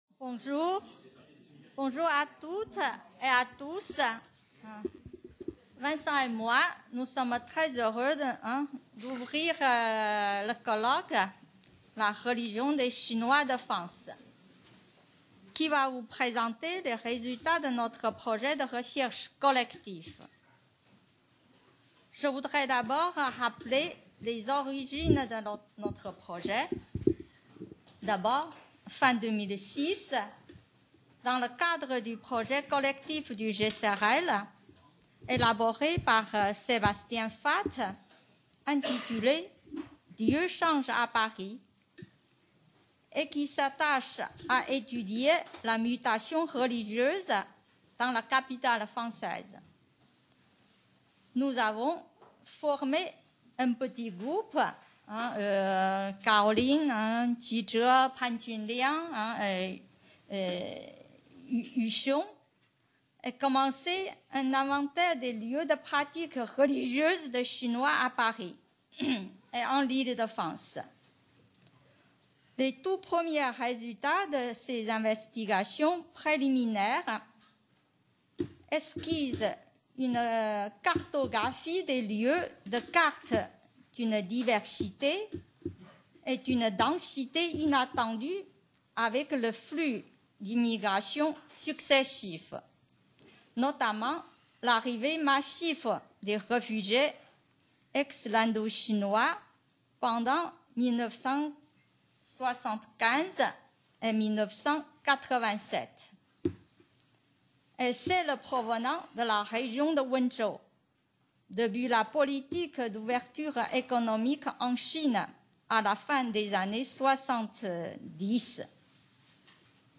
Colloque international du 18 au 20 mai 2016 au CNRS site Pouchet, Paris 17e et à l'INALCO, Paris 13e.